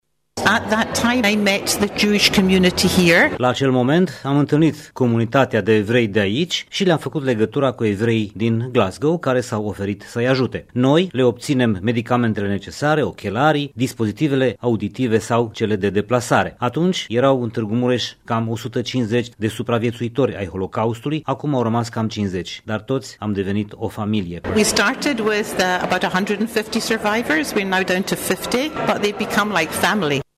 Primaria Tîrgu-Mureș a organizat, azi, în Sala de Oglinzi a Palatului Culturii, o recepție comemorativă la 7 decenii de la eliberarea lagărului de exterminare de la Auschwitz.